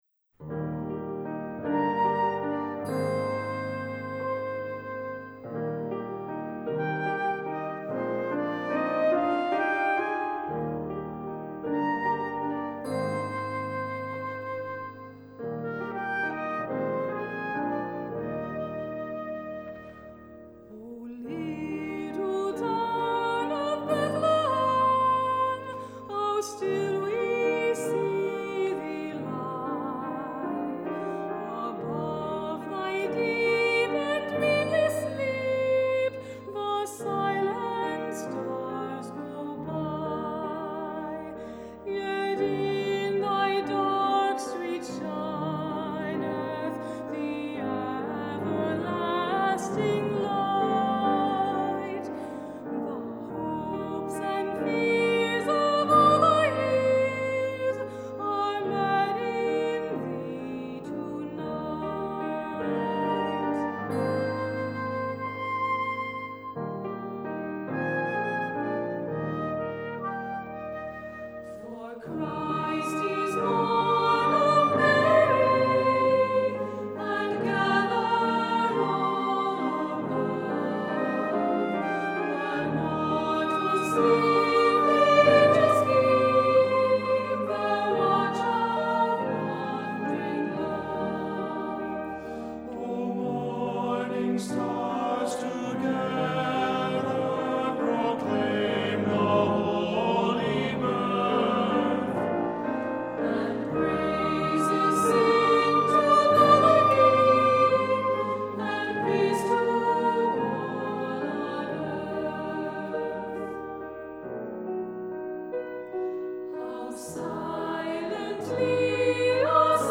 Accompaniment:      Keyboard, Flute
Music Category:      Choral